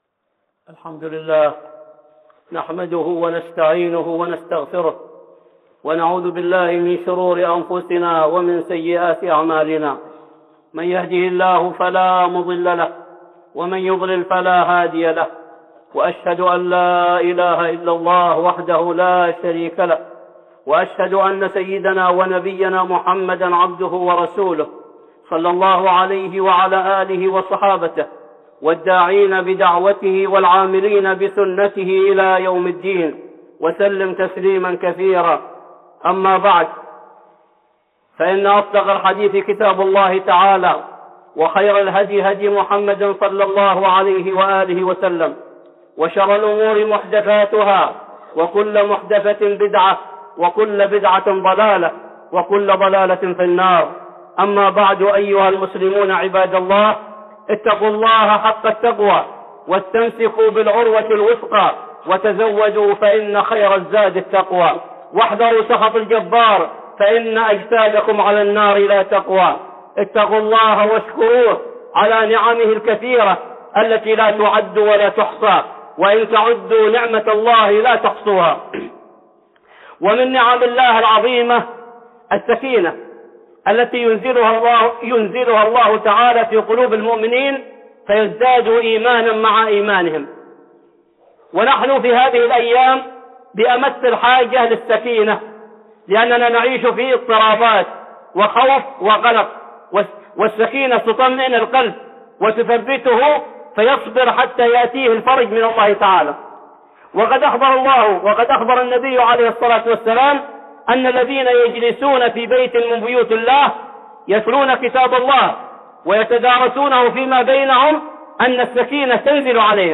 (خطبة جمعة) آيات السكينة